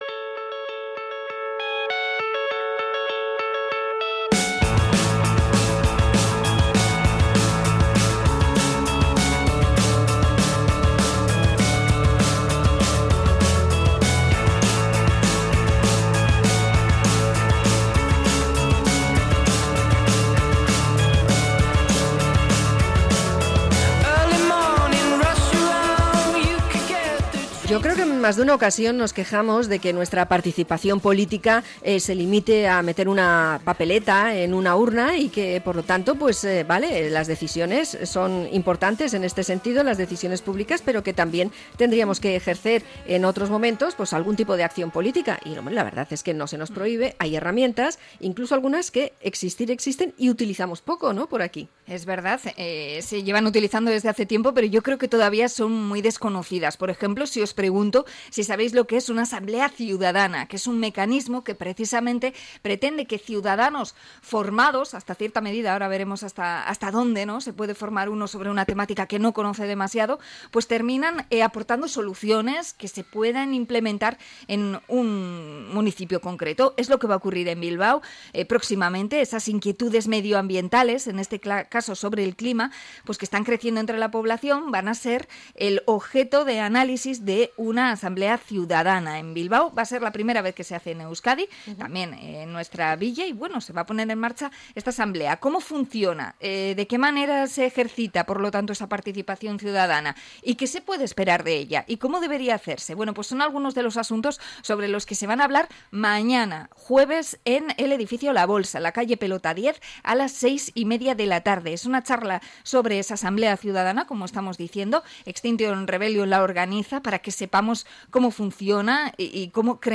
Entrevista a Extinction Rebellion sobre la Asamble Ciudadana por el Clima de Bilbao